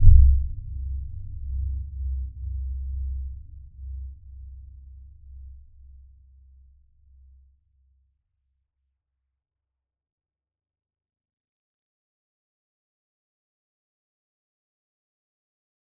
Dark-Soft-Impact-C2-f.wav